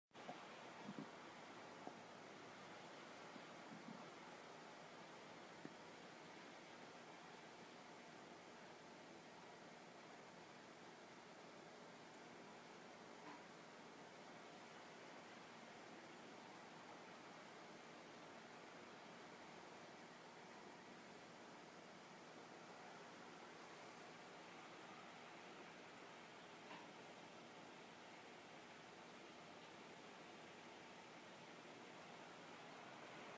白噪声 " 白噪声1
描述：通用白噪声，柔和的颗粒状嗡嗡声
Tag: 白噪声 一般的噪声 低沉的风机 通用